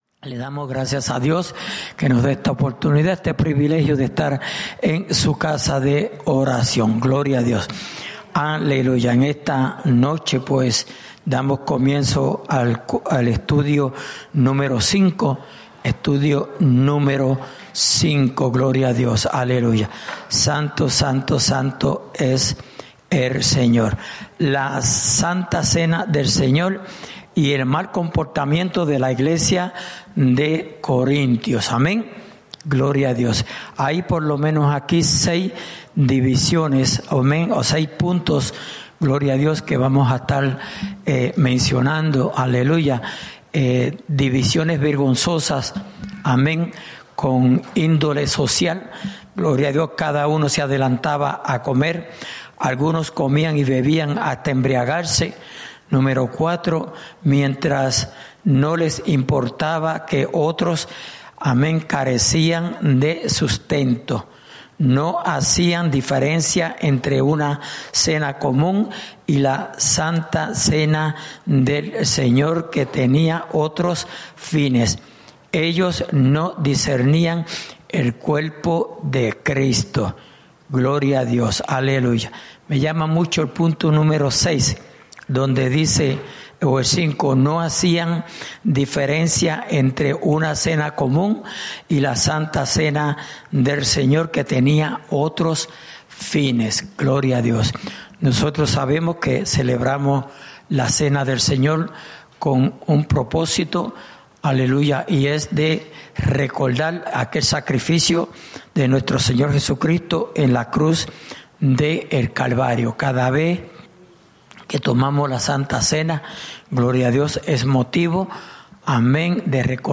Estudio Bíblico: La Cena del Señor (Parte 5)